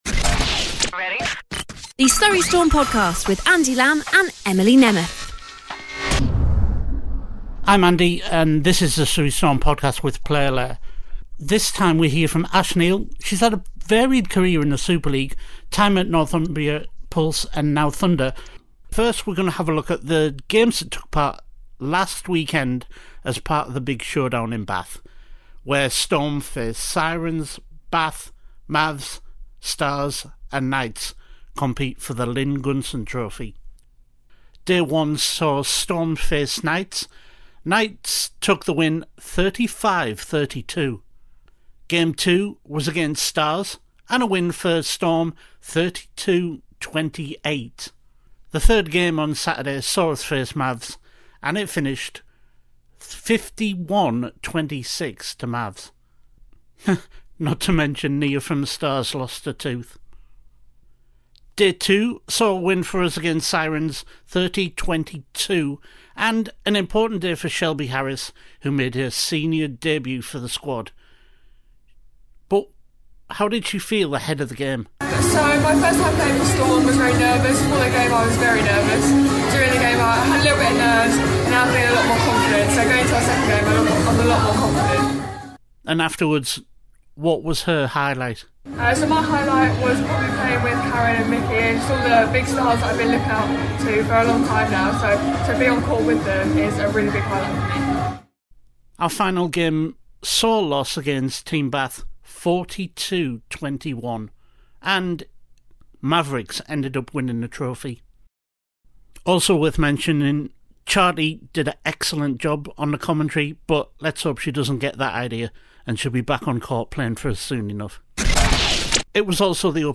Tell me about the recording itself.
direct from Surrey Sports Park